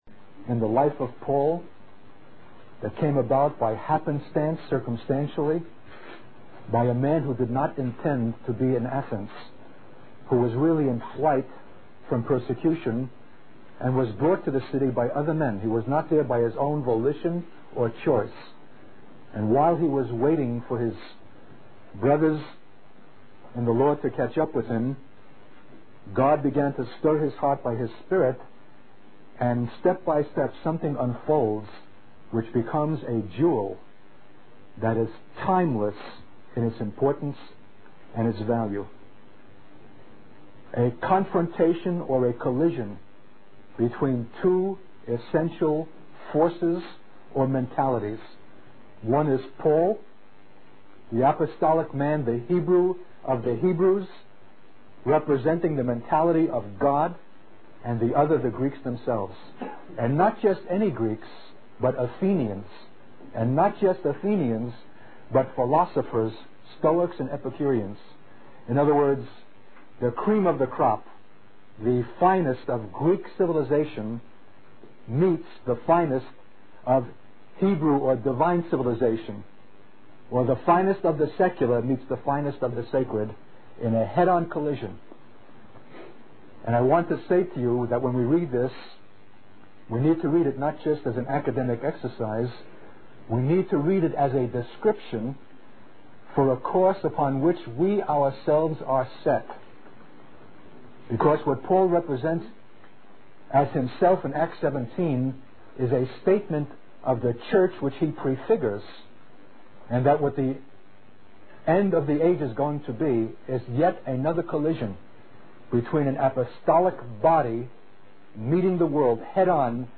In this sermon, the speaker emphasizes the power of the Word of God and its ability to penetrate and bring transformation to communities.